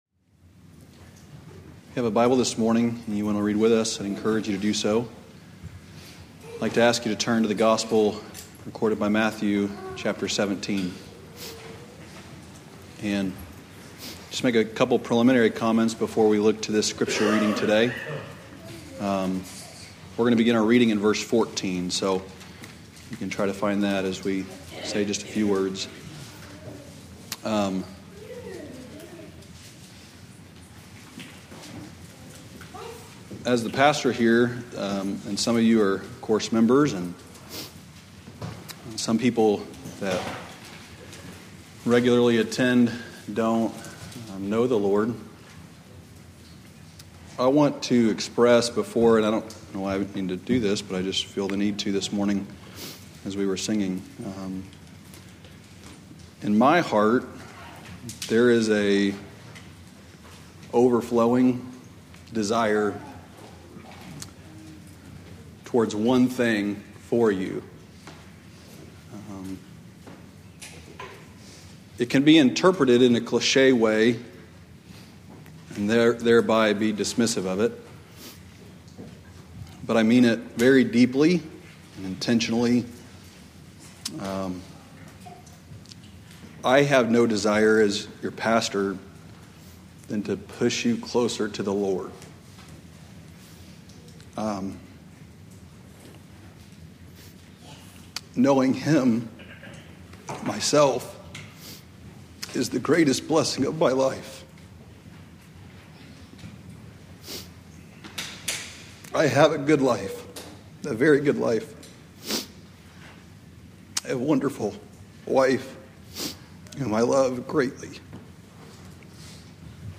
Below is the last six sermons from the pulpit.
Sermons from our Sunday morning worship services.